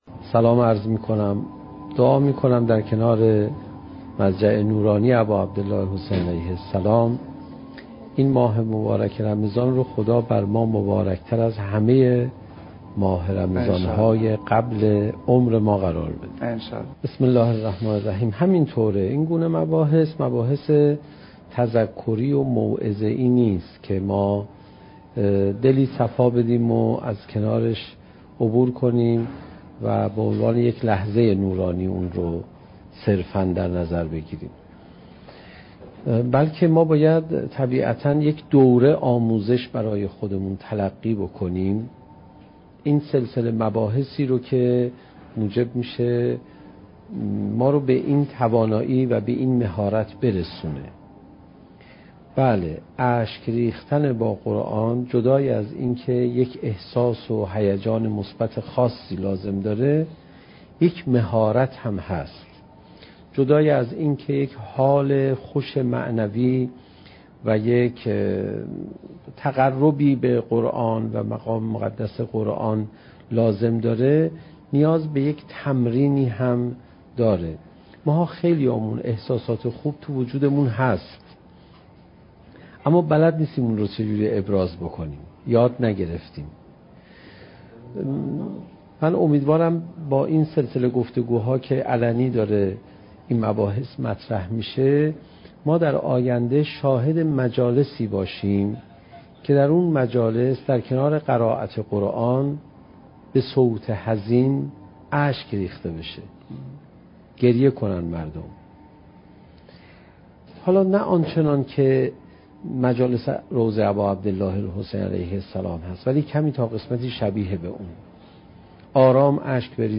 سخنرانی حجت الاسلام علیرضا پناهیان با موضوع "چگونه بهتر قرآن بخوانیم؟"؛ جلسه سوم: "مفهوم اشک ریختن با قرآن"